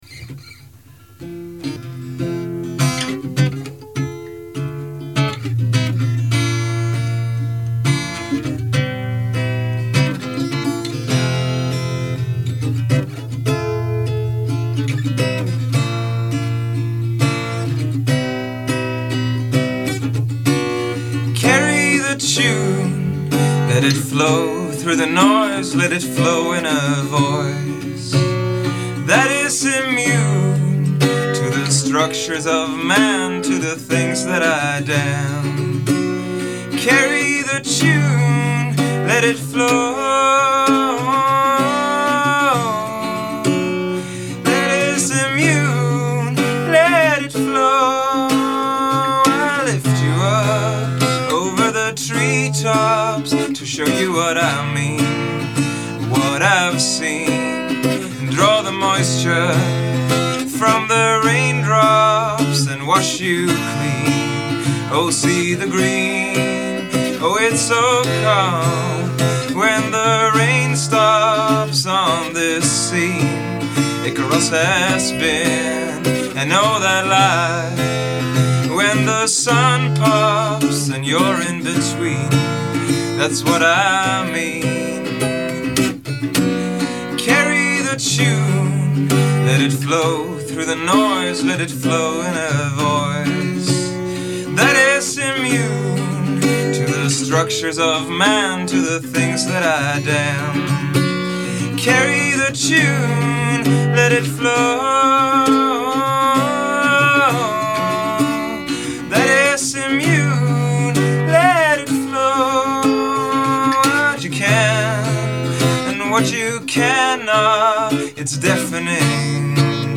le canzoni delicate e sospese